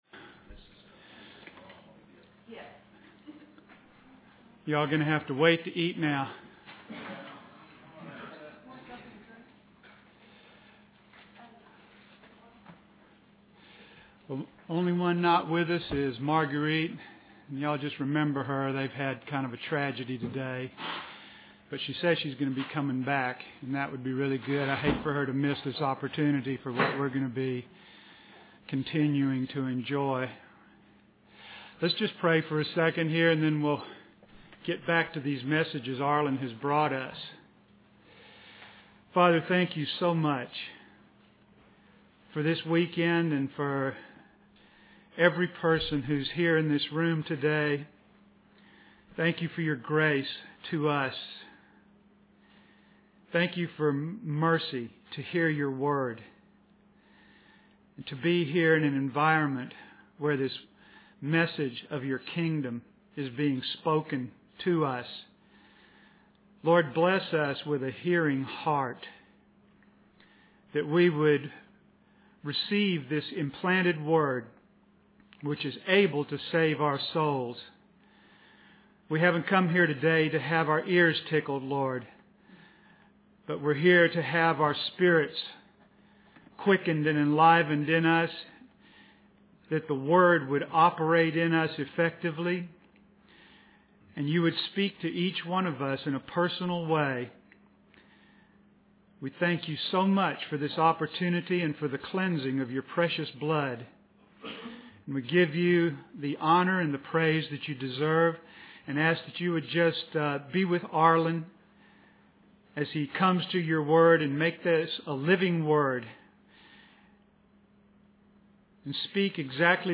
In this sermon, the speaker focuses on Genesis 19:17 and its relevance to the destruction of the nations.